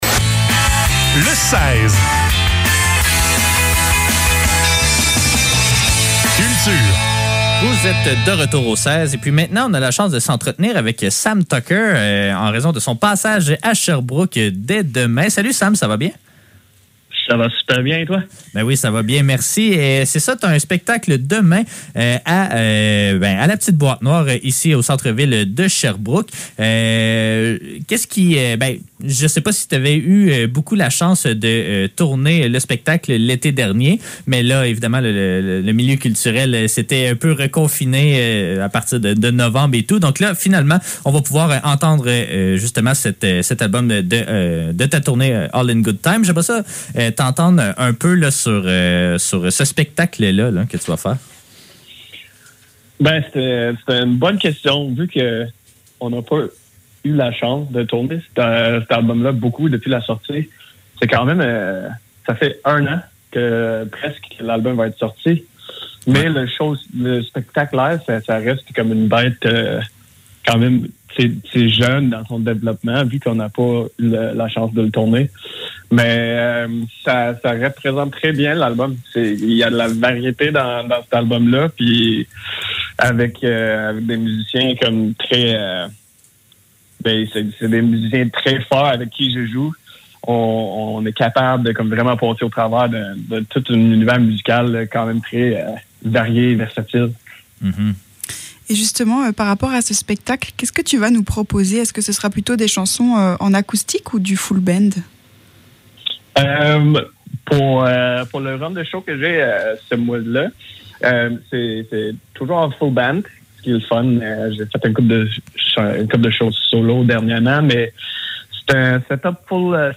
Le seize - Entrevue